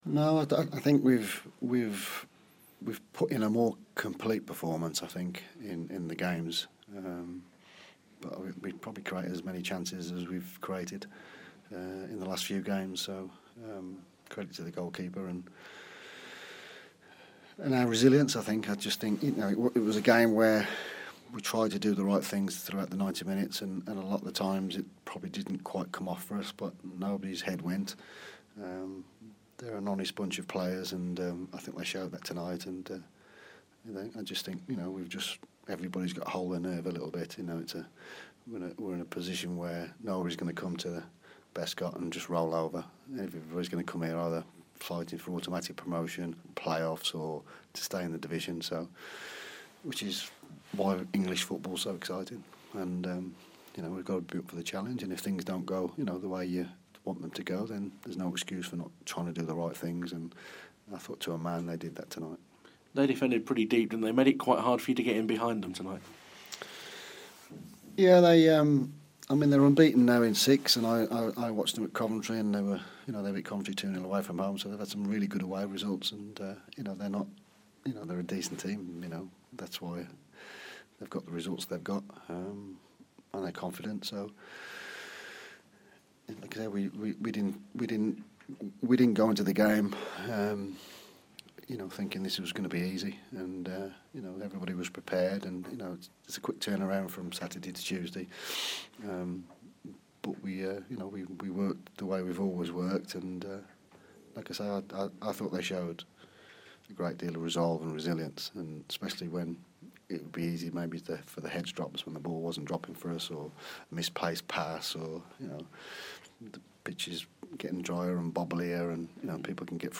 speaks to BBC WM after the 0-0 draw with Scunthorpe